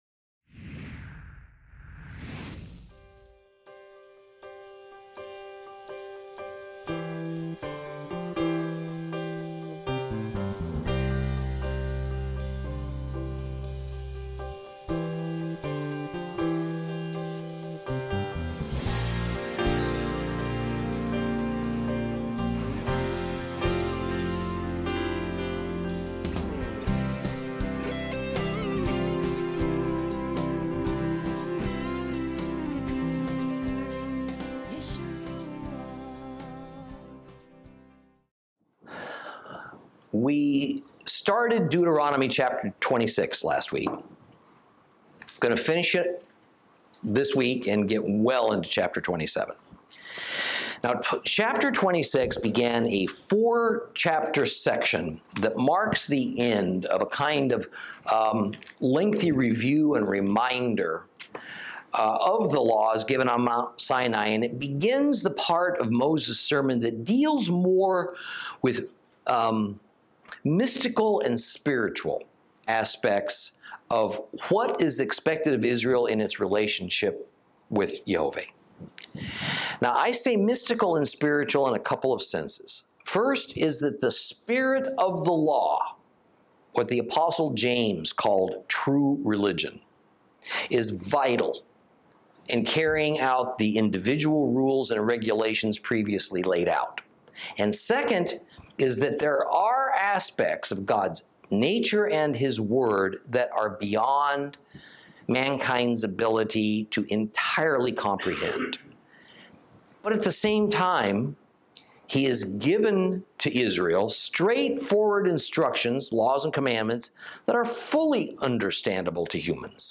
Lesson 36 Ch26 Ch27 - Torah Class